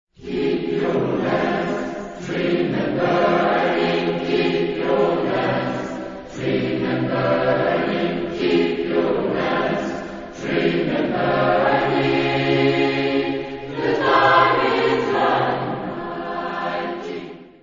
Genre-Style-Form: Spiritual ; Sacred
Mood of the piece: religious
Type of Choir: SATB  (4 mixed voices )
Tonality: F minor